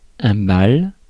Ääntäminen
Synonyymit soirée boîte de nuit boite de nuit bastringue Ääntäminen France: IPA: [bal] Paris Haettu sana löytyi näillä lähdekielillä: ranska Käännös 1. ball Suku: m .